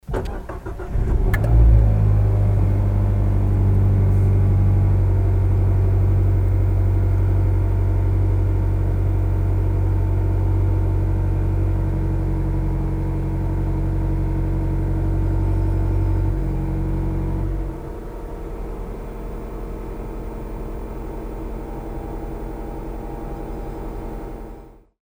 実際に車内の運転席にて、騒音を収録してみました。
ガソリンデミオ（温間時のアイドリング～空ふかし）
非常に静かになりますが
g_demio_idling.mp3